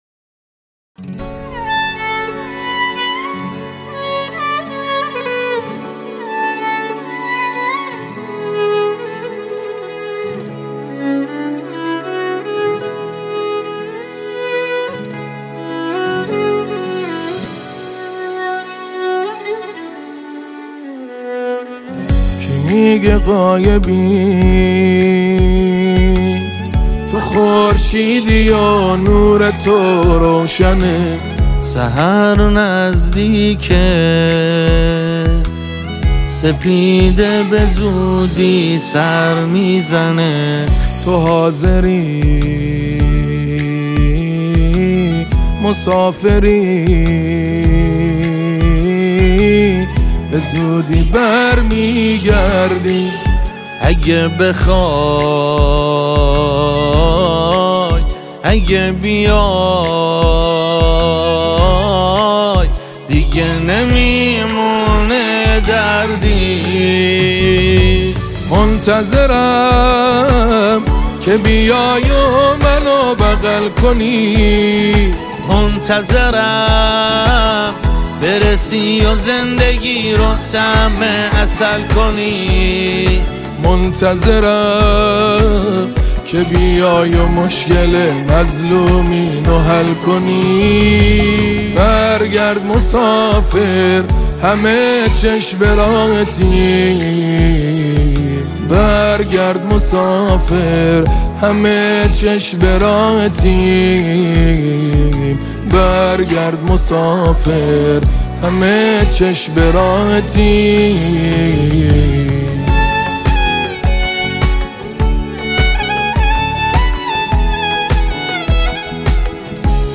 سرود مذهبی ، سرود مناسبتی